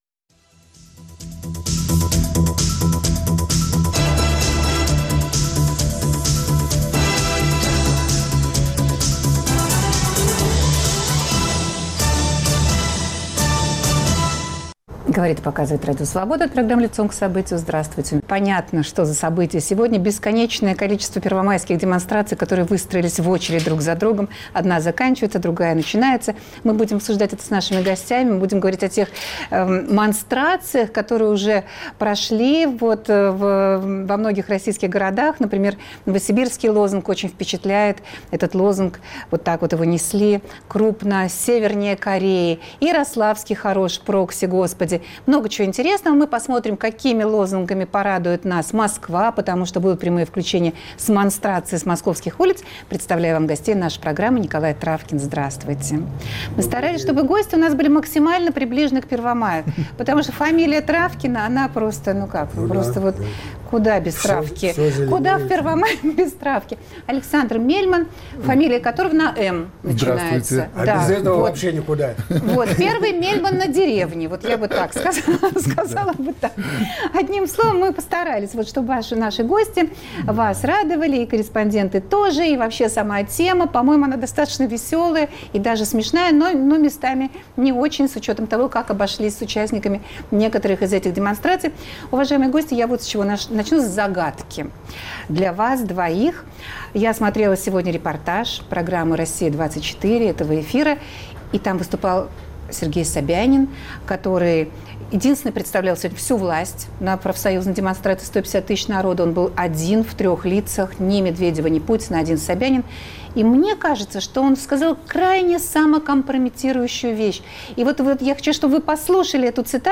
Прямые включения с улиц Москвы.